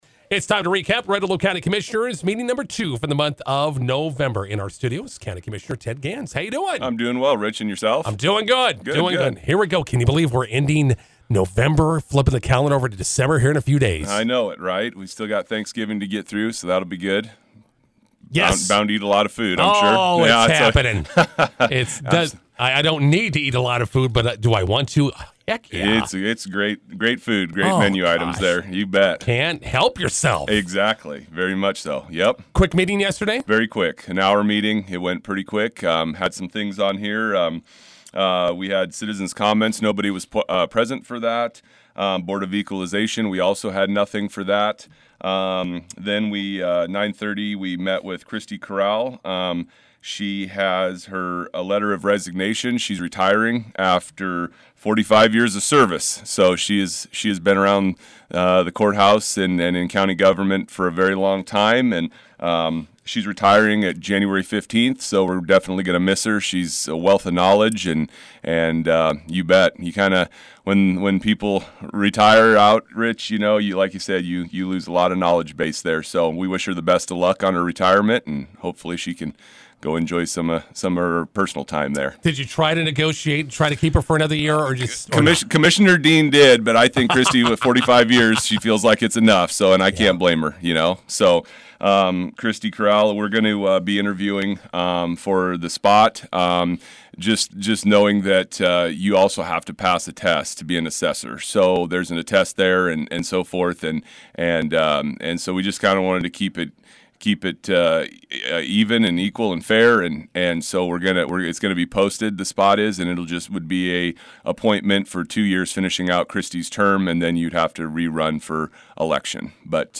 INTERVIEW: Red Willow County Commissioners meeting recap with County Commissioner Ted Gans.